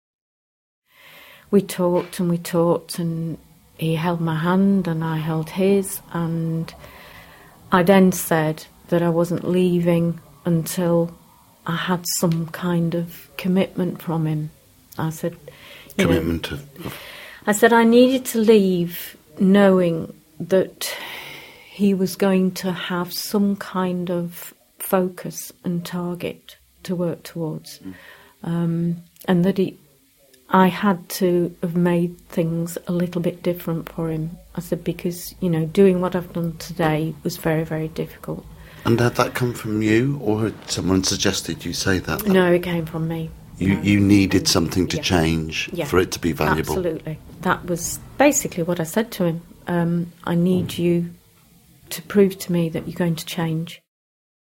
A short clip from the upcoming show on Radio 4